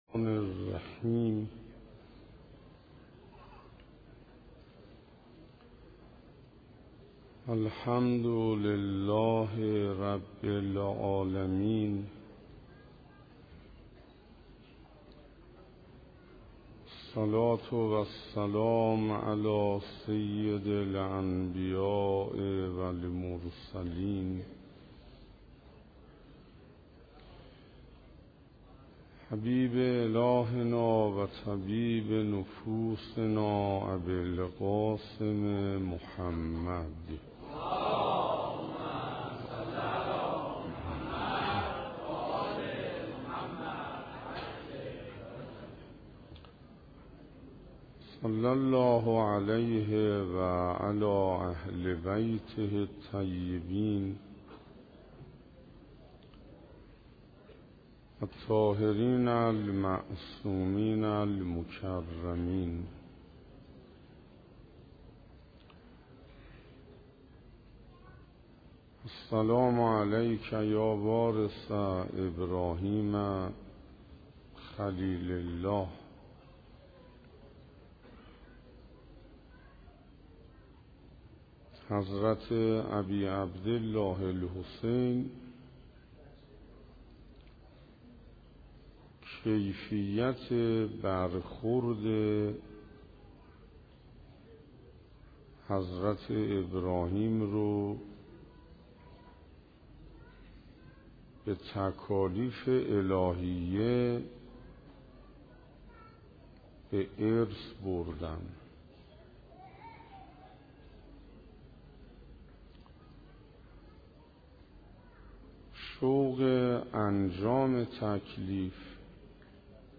شرح زیارت وارث - سخنراني هفتم - محرم 1435 - مسجد امیر